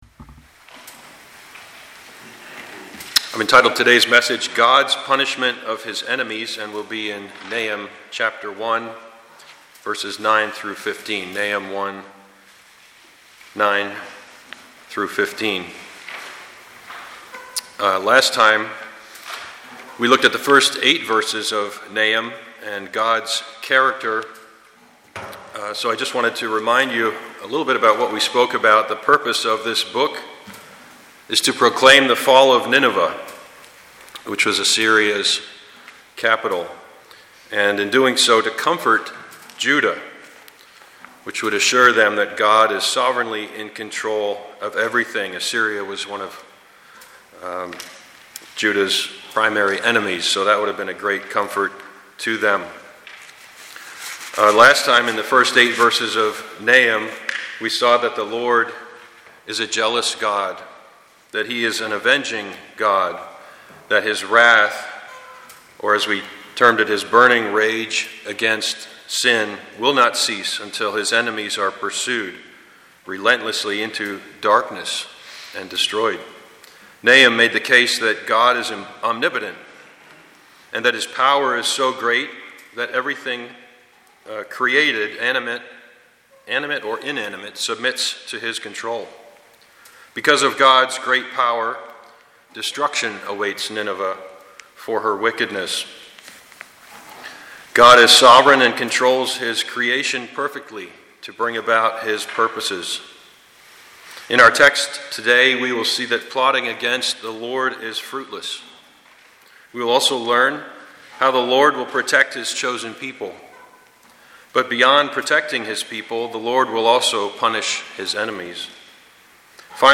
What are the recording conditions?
Passage: Nahum 1:9-15 Service Type: Sunday morning